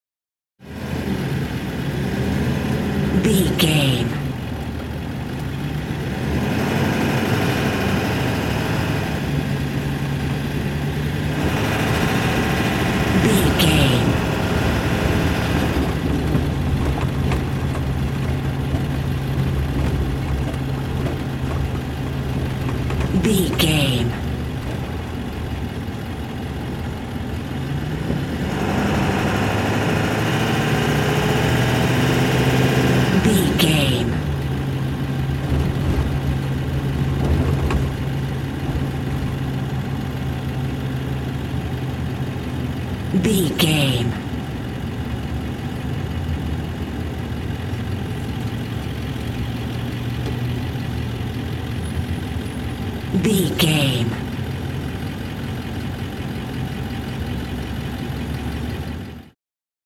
Ambulance Int Drive Diesel Engine Slow
Sound Effects
urban
emergency